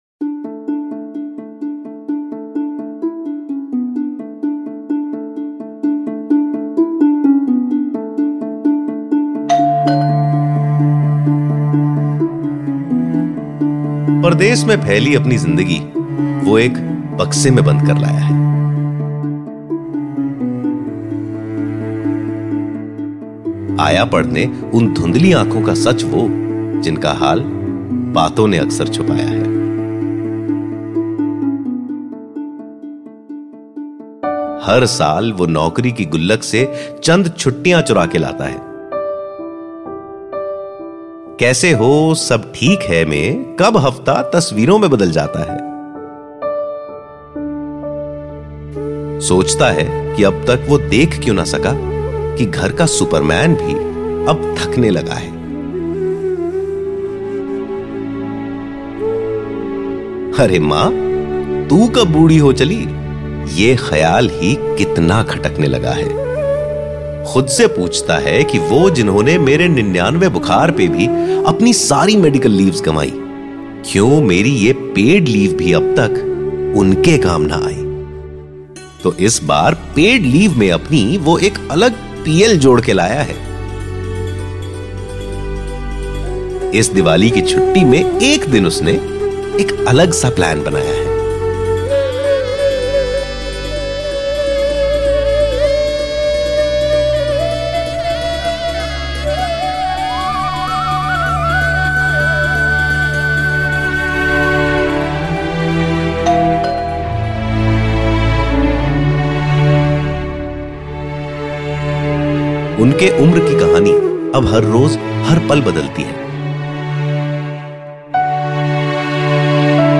His voice can be described as: deep, baritone, warm, friendly, intense, informed, reassuring, joyful, commanding.
Hindi Sample